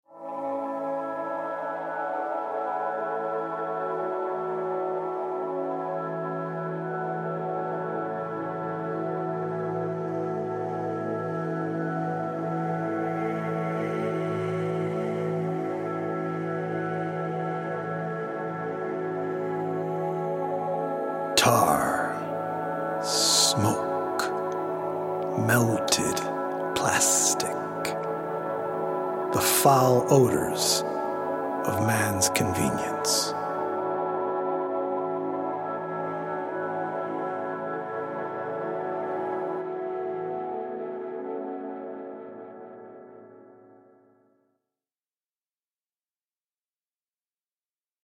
healing Solfeggio frequency music
EDM producer